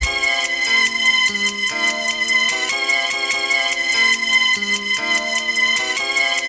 Memphis Piano Loop.wav